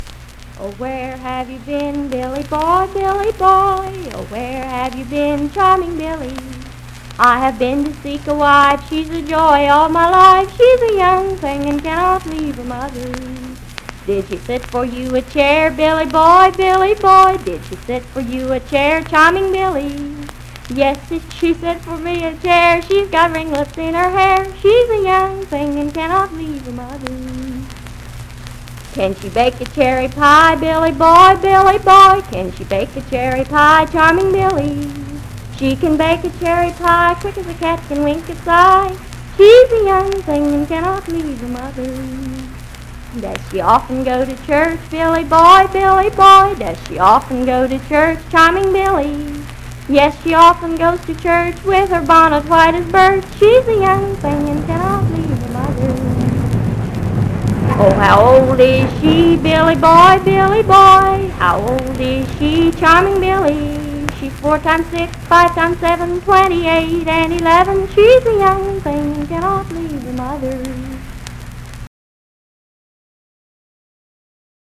Unaccompanied vocal music
Children's Songs, Dance, Game, and Party Songs
Voice (sung)
Wood County (W. Va.), Parkersburg (W. Va.)